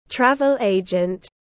Đại lý du lịch tiếng anh gọi là travel agent, phiên âm tiếng anh đọc là /ˈtrævl eɪdʒənt/.
Travel agent /ˈtrævl eɪdʒənt/